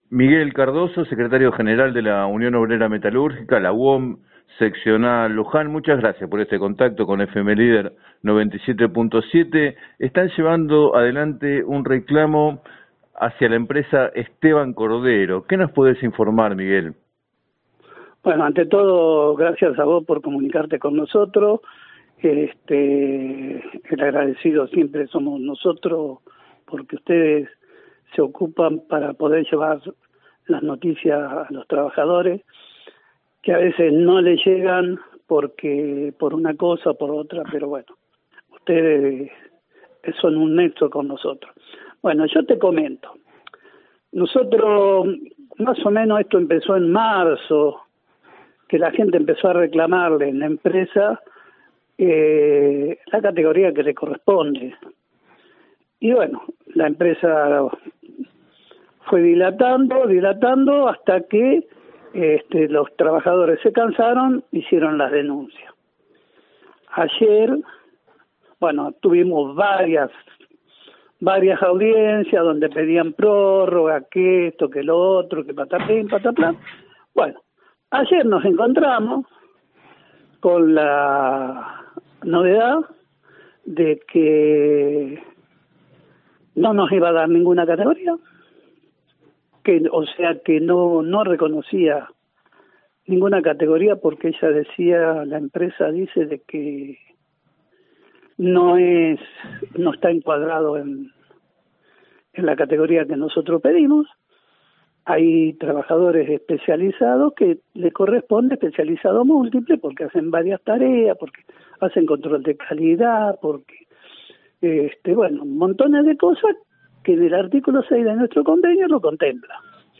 En declaraciones a “7 a 9 Música y Noticias” de FM Líder 97.7